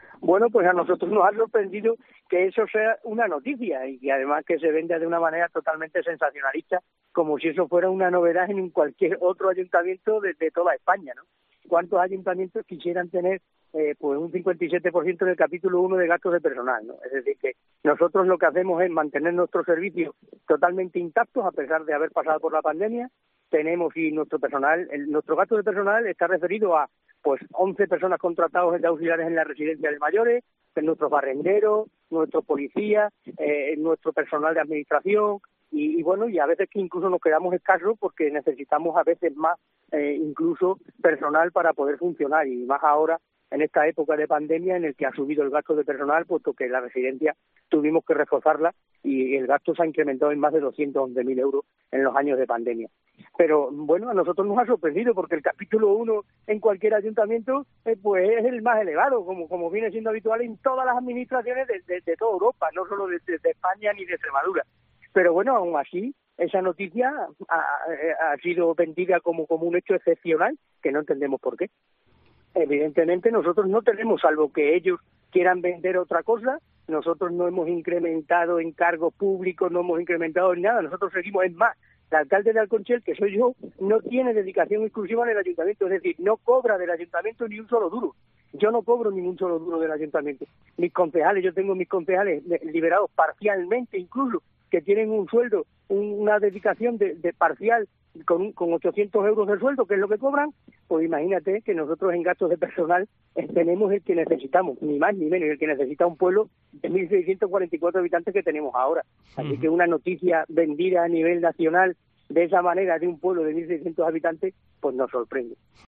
El alcalde de Alconchel, en Cope: "El gasto que tenemos de personal es lo normal en cualquier Ayuntamiento"